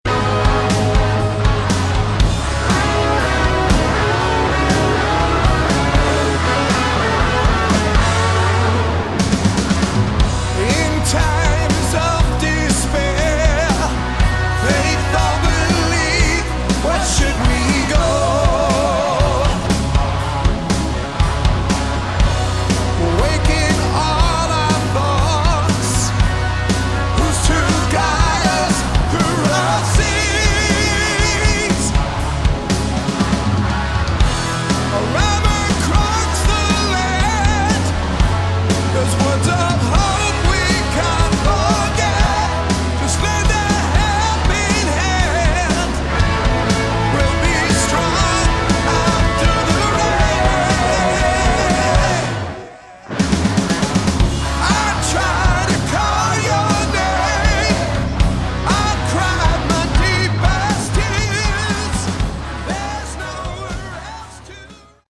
Category: Hard Rock
Vocals
Guitars, keyboards, bass
Drums